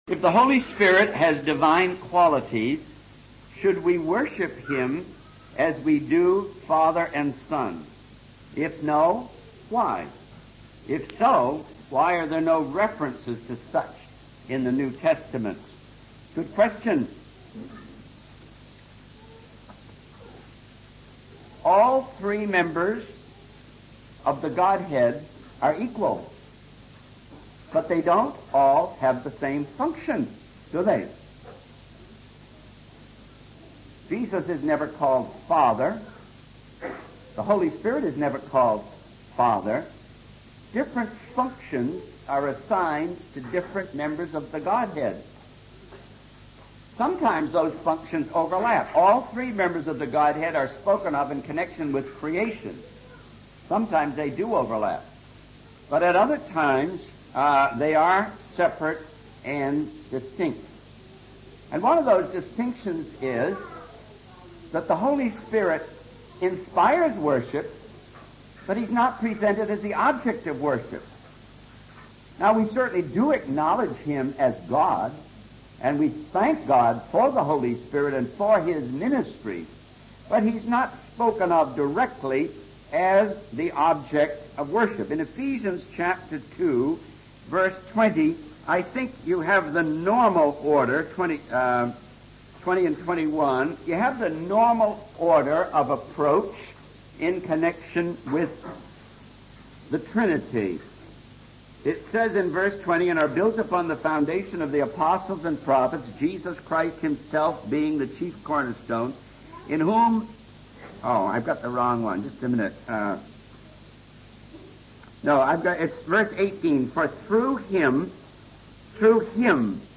In this sermon, the speaker discusses the ministries of the Holy Spirit of God. The first ministry mentioned is the conviction of the unsaved, where the Holy Spirit shows people their need for Jesus and urges them to trust in Him.